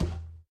Minecraft Version Minecraft Version snapshot Latest Release | Latest Snapshot snapshot / assets / minecraft / sounds / mob / irongolem / walk3.ogg Compare With Compare With Latest Release | Latest Snapshot
walk3.ogg